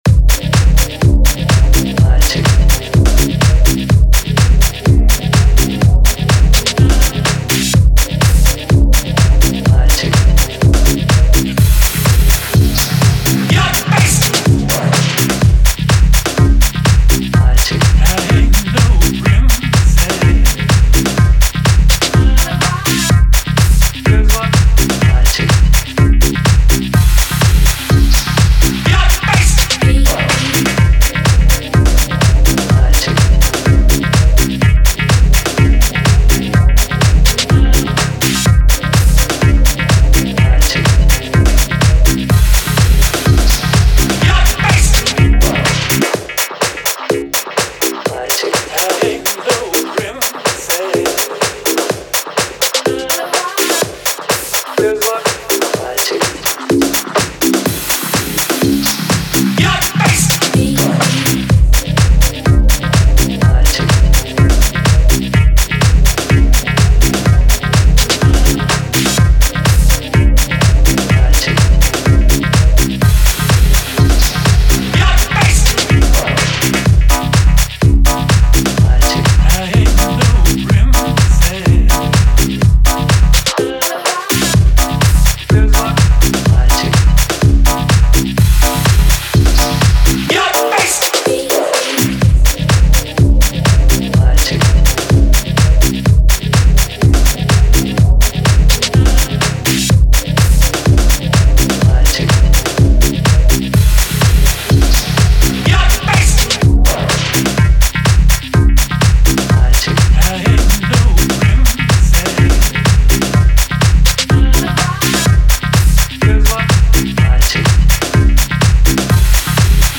Here’s a recent beat I made with the DN2 and OT